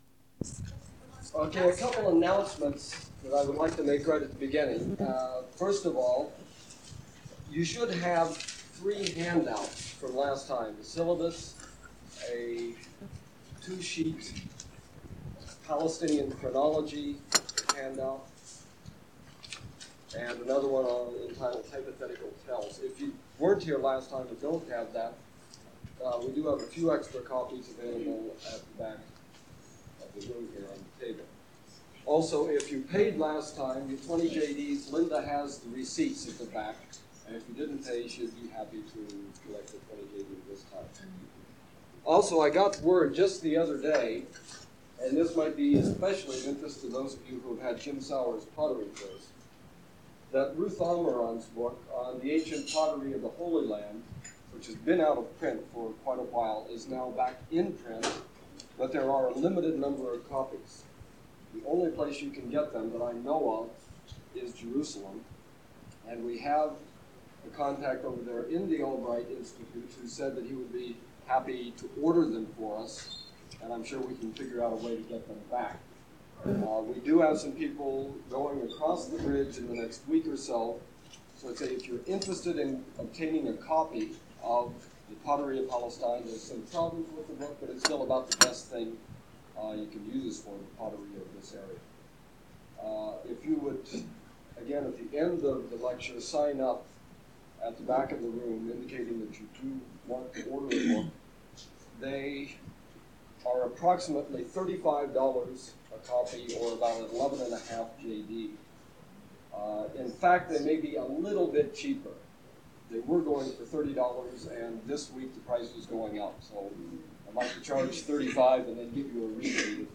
Archaeology of Jordan and Biblical History - Lecture 2: Chronology, Means of dating occupation layers and structures
Format en audiocassette ID from Starchive 417968 Tag en Excavations (Archaeology) -- Jordan en Bible -- Antiquities en Archaeology Item sets ACOR Audio-visual Collection Media Arch_Bible_02_access.mp3